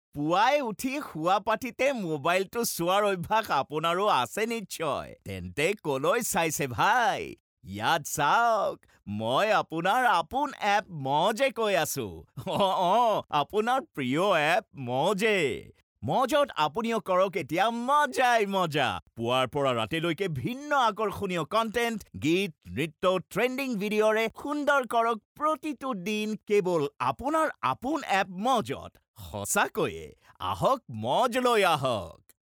– ナレーション –